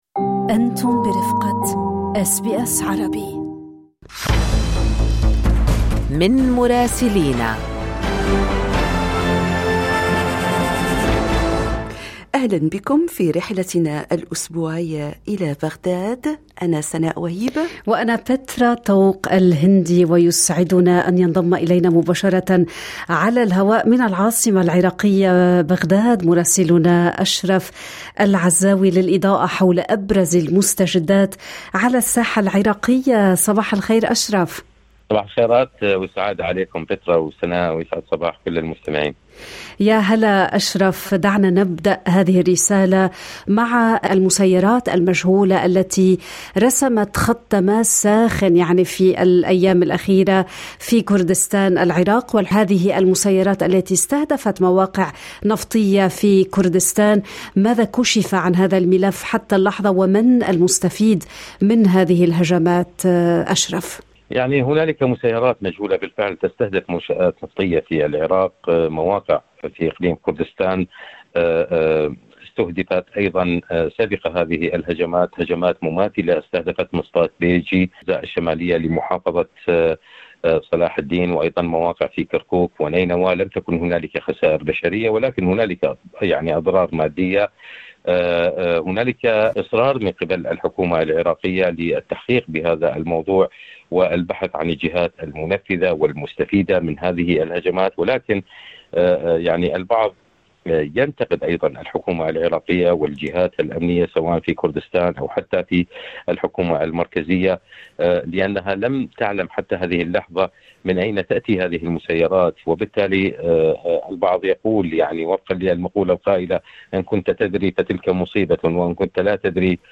تقرير المراسل